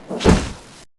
Heroes3_-_Royal_Griffin_-_DefendSound.ogg